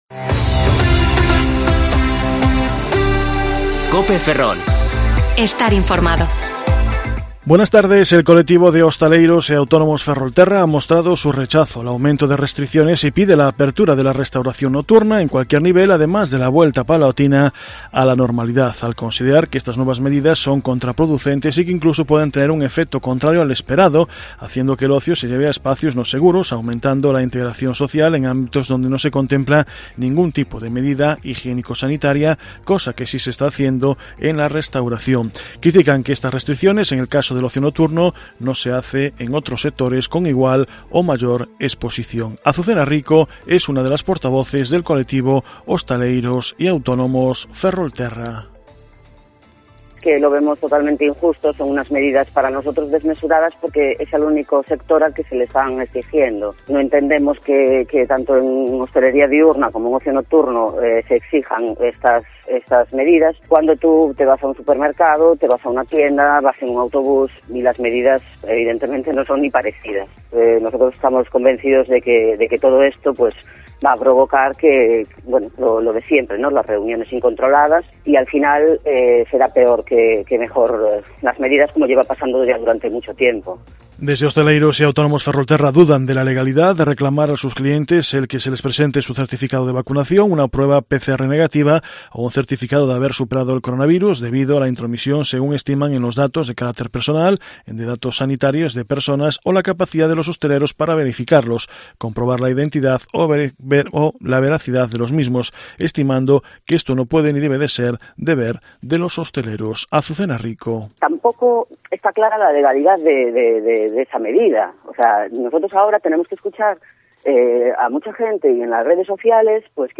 Informativo Mediodía COPE Ferrol 1/8/2021 (De 14,20 a 14,30 horas)